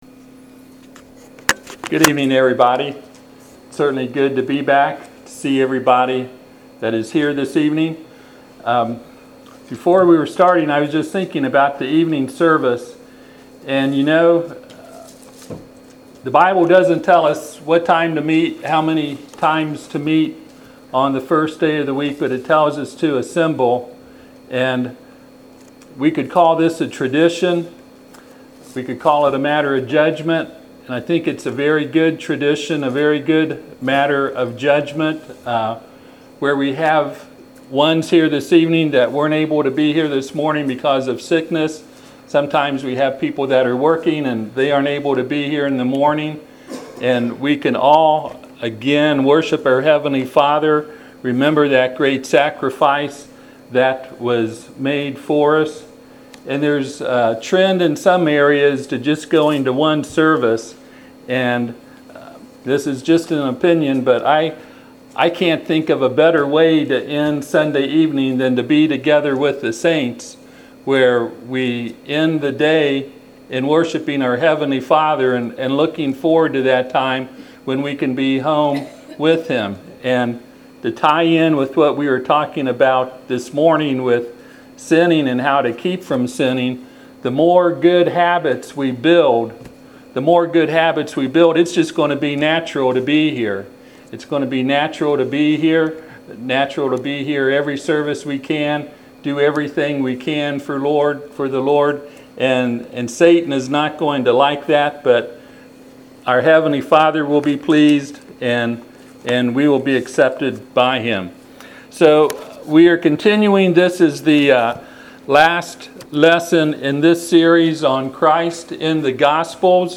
Passage: John 20:30-31 Service Type: Sunday PM